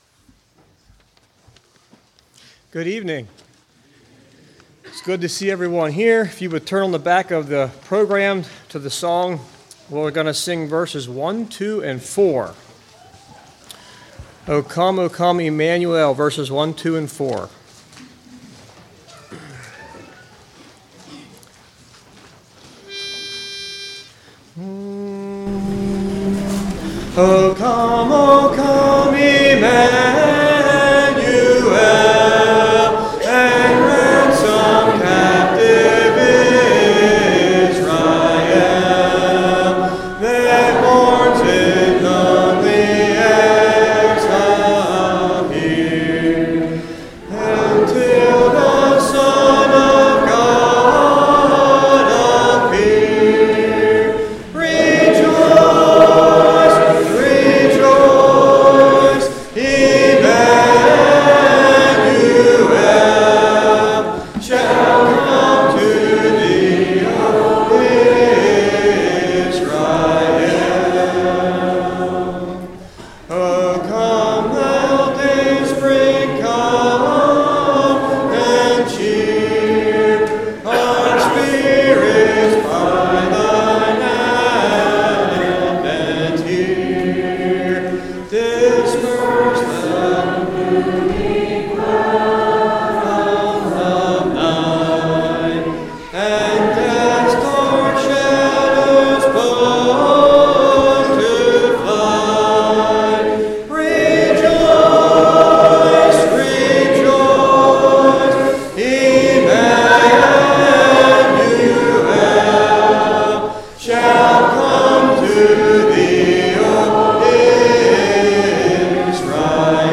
“O Come, O Come, Emmanuel” – Congregation
Service Type: Evening
Student Body
Grades 5-12 Girls